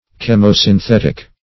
-- Chem`o*syn*thet"ic, a.